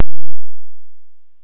Lecture_DigitalDivide_11_8_06.wav